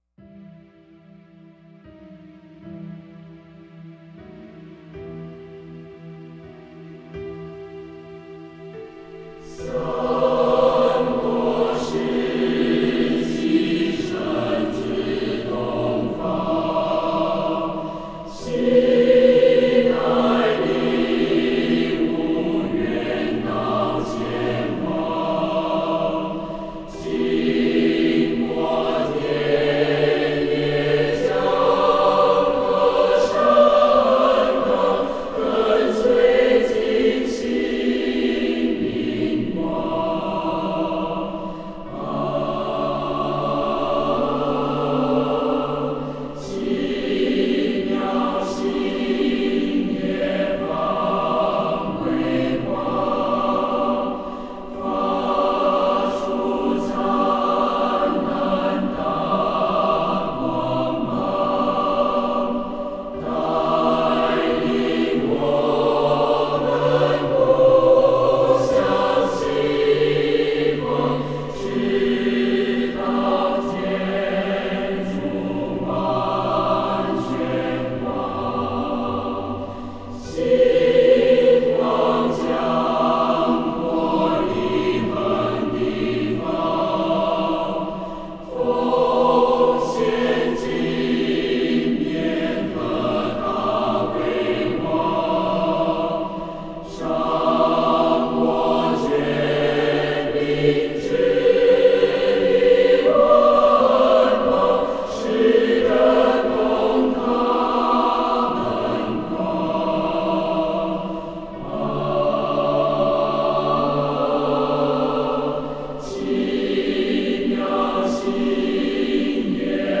歌的旋律活泼，带有浓厚的民谣气息，它的节奏令人感到骆驼沉重的步伐。